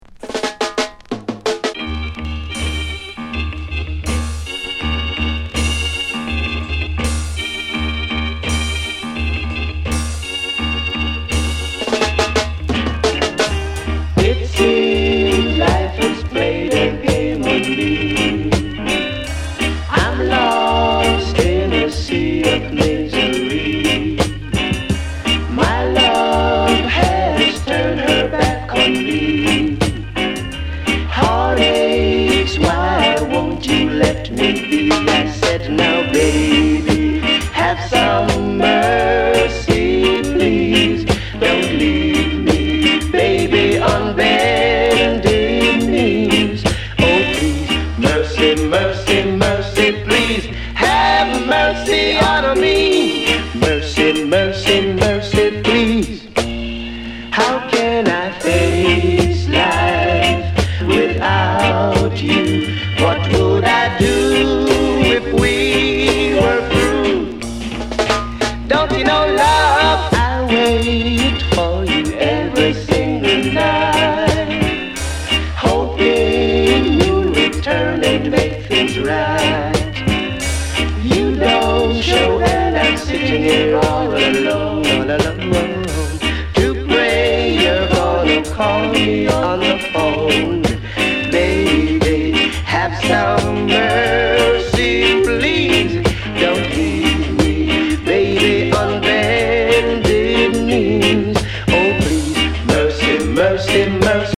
LP]ロックステディーレゲエ